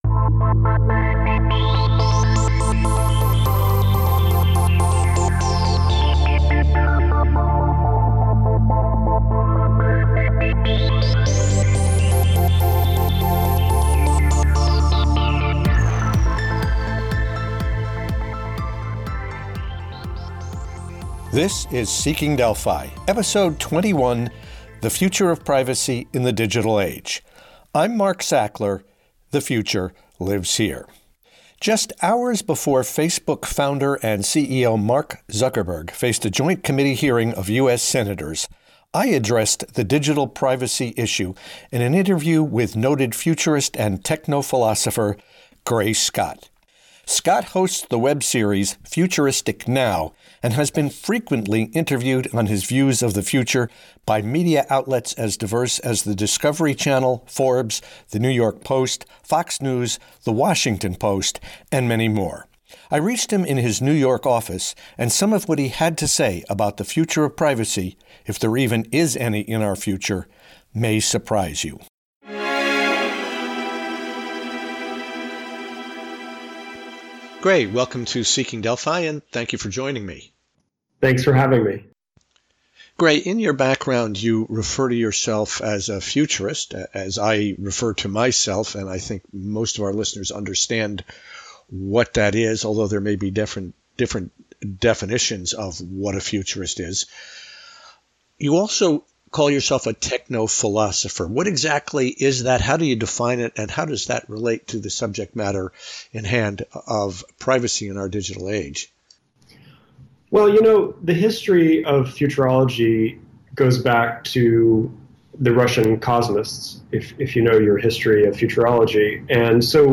It seems we’d rather have free content–even at the cost of privacy–than pay even nominal amounts to access online materials. In this wide ranging interview